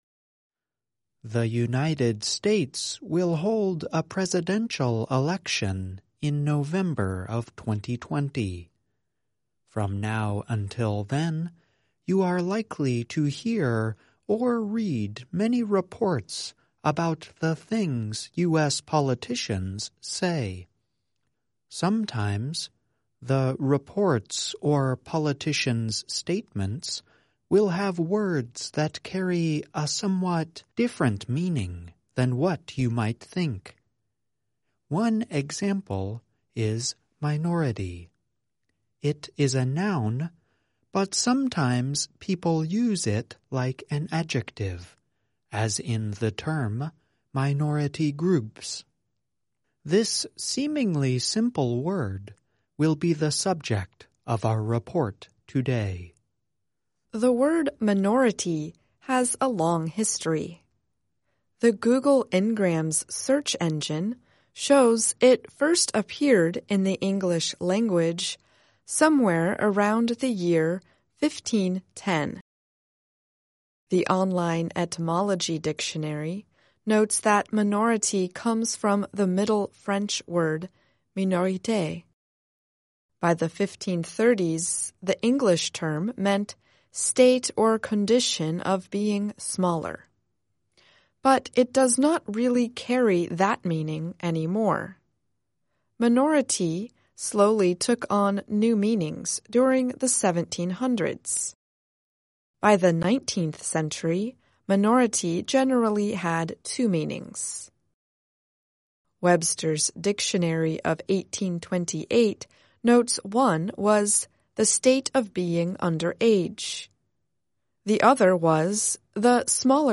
**There are two main pronunciations of minority. They are: maɪˈnɔːrəti and məˈnorəti